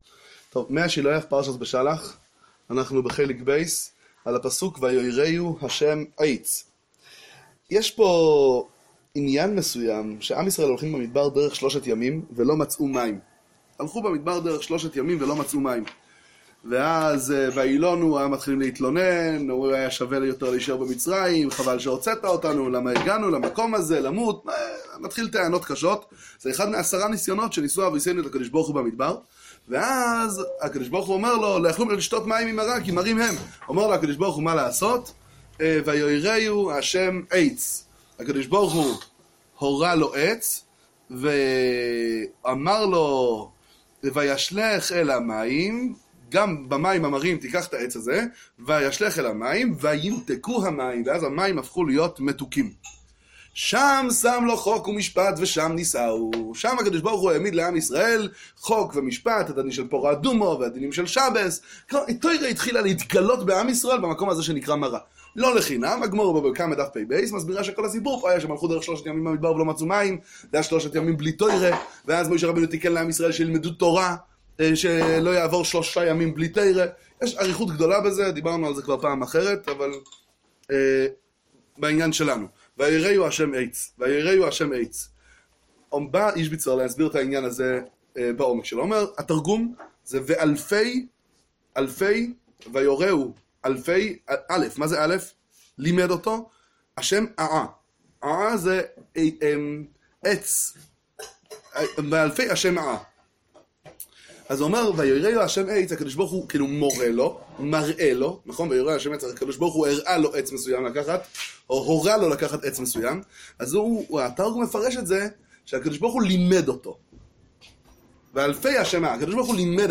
שיעור לשמיעה בספר מי השילוח איז'ביצע על פרשת השבוע